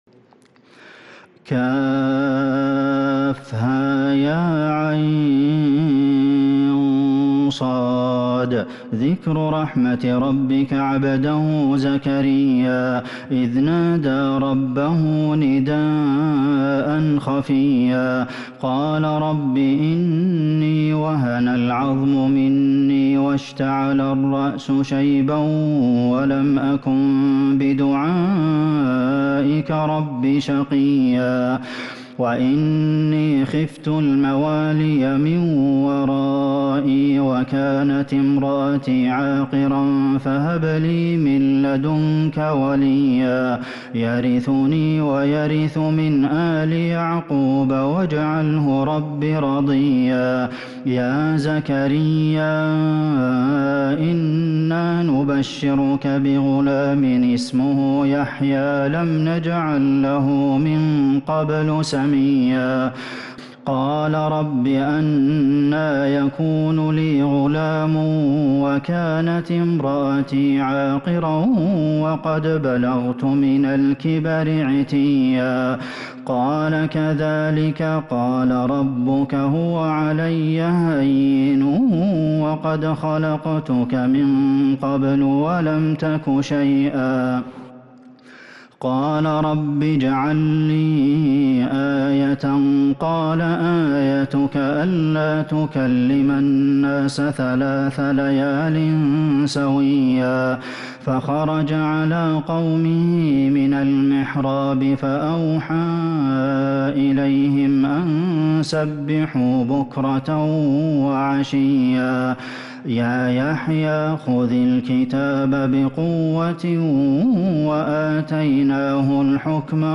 سورة مريم كاملة من تراويح الحرم النبوي 1442هـ > مصحف تراويح الحرم النبوي عام 1442هـ > المصحف - تلاوات الحرمين